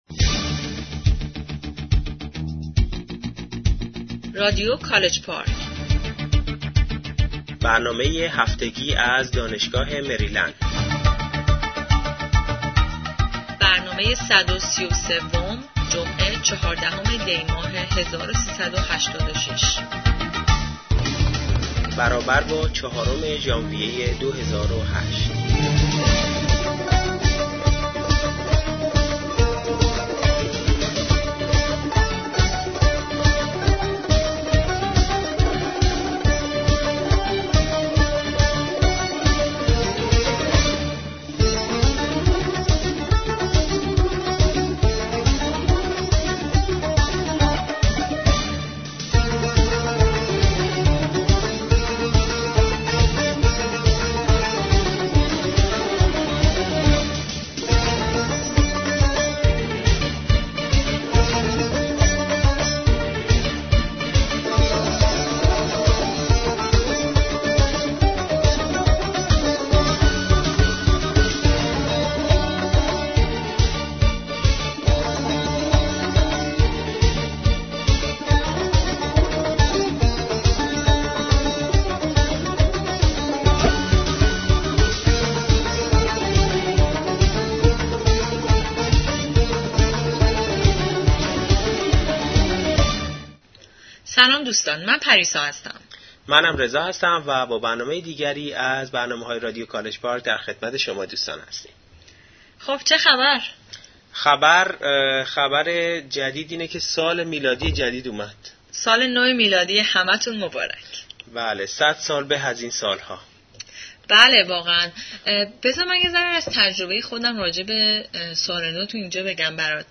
Weekly News